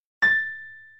AK_Piano_6dd.wav